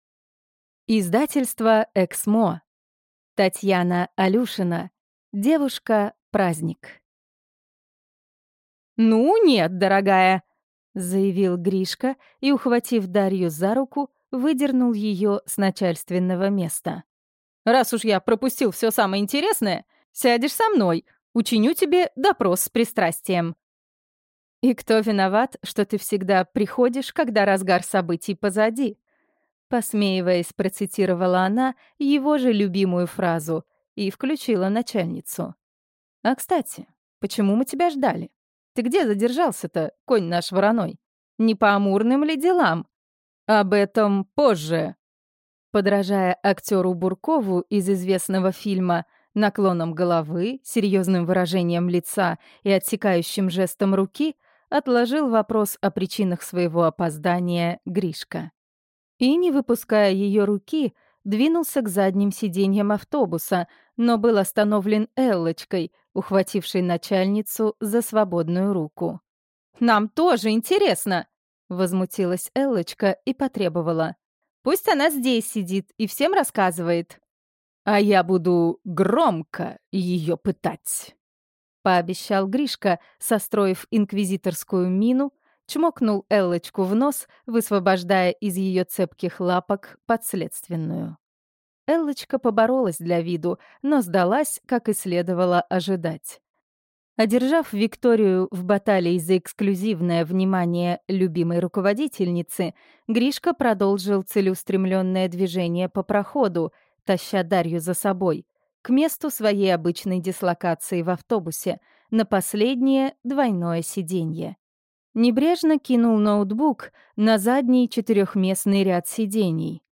Слушать аудиокнигу Привычка быть счастливым. 12 интервью о счастье полностью